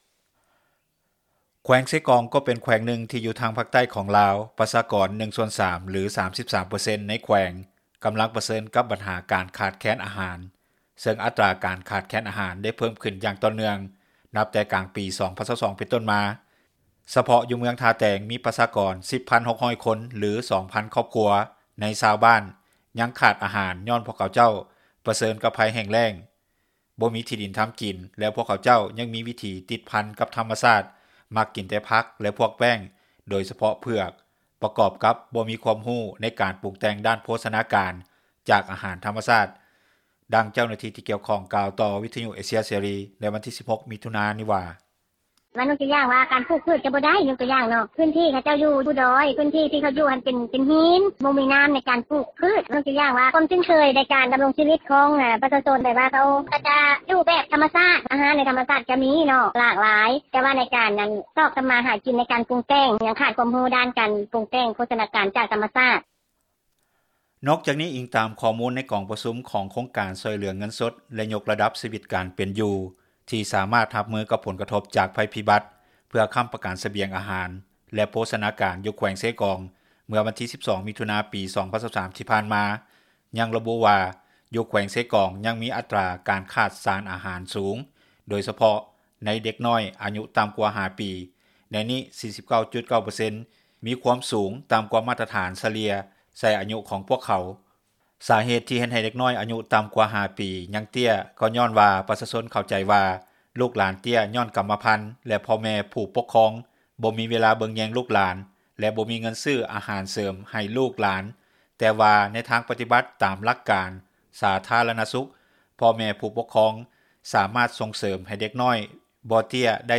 ດັ່ງເຈົ້າໜ້າທີ່ ທີກ່ຽວຂ້ອງ ກ່າວຕໍ່ວິທຍຸ ເອເຊັຽ ເສຣີ ໃນວັນທີ 16 ມິຖຸນາ ນີ້ວ່າ: